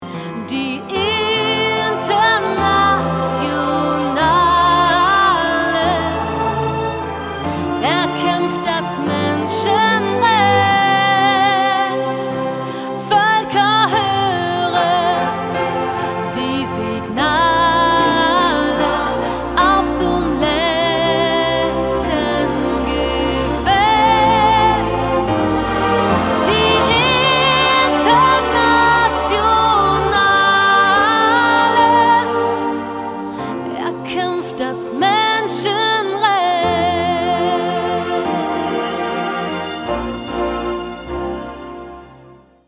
key: F-major